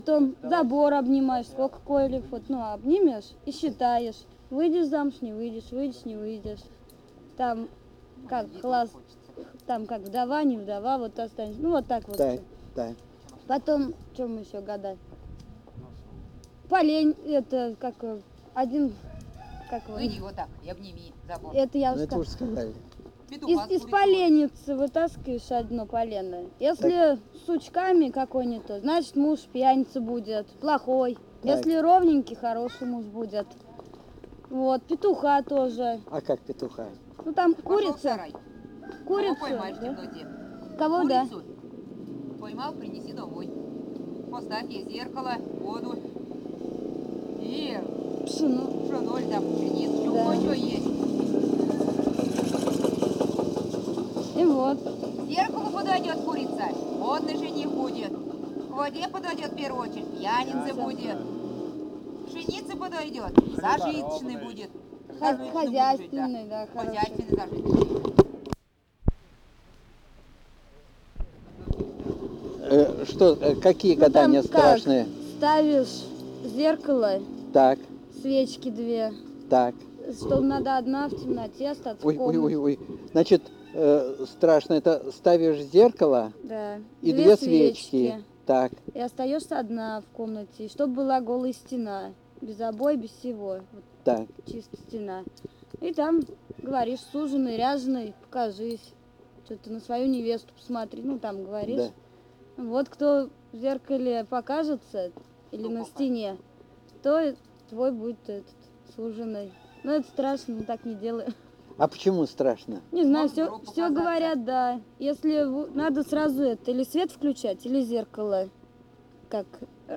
Рассказ